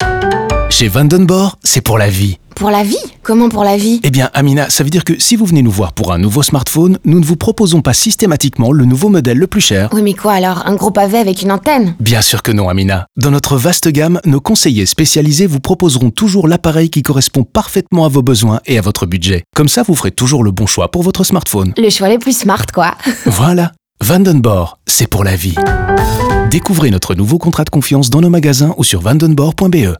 La voix off entame ainsi à chaque fois la conversation avec différents clients et répond à toutes leurs questions.
Enfin, notons également que le logo sonore a lui aussi été légèrement modifié, même si la célèbre mélodie reste inchangée et résonne probablement déjà dans votre tête.
Vanden Borre-Radio-Conseil.wav